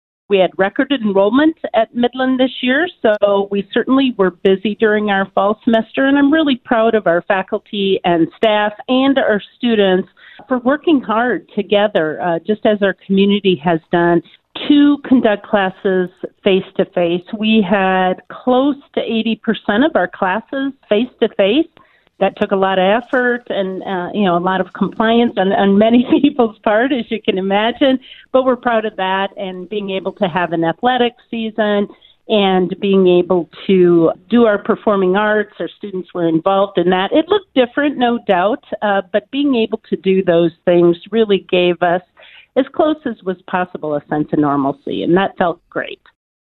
was a guest on the KHUB Morning Show on Thursday